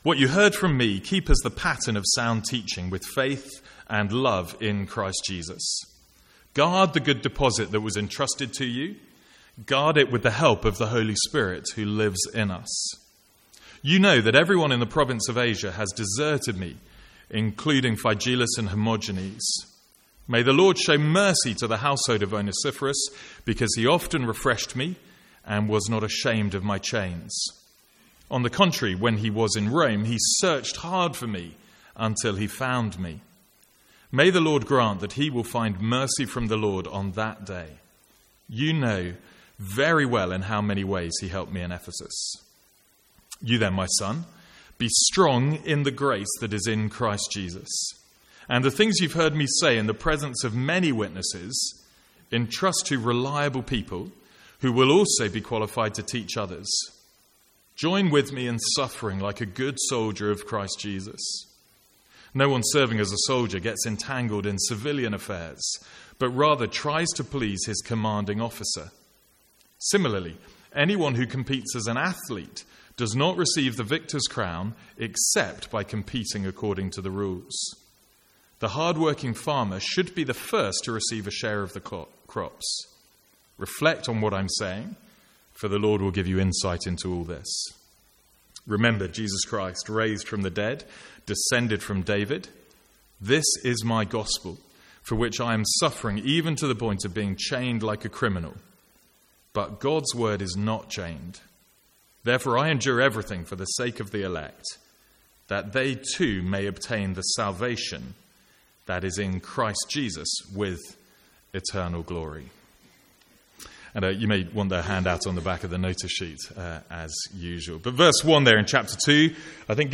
A one-off Sunday morning sermon on 2 Timothy1:13-2:10.